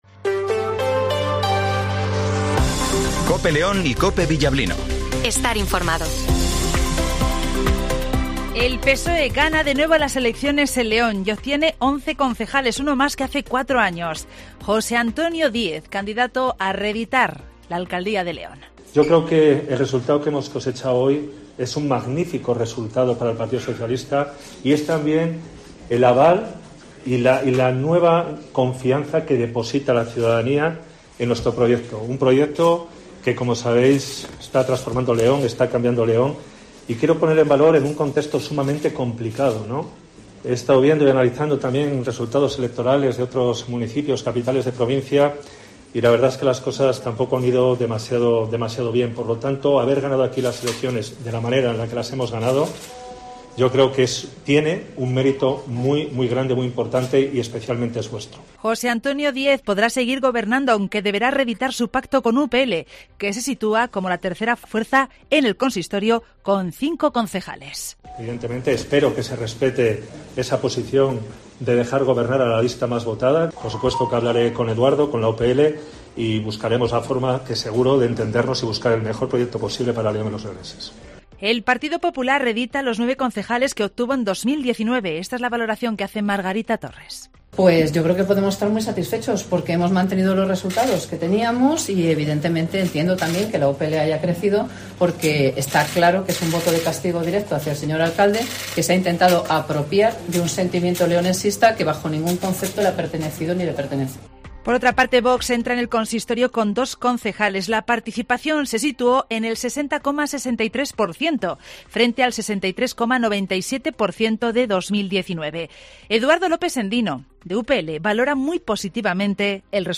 Inormativo matinal 08:20 h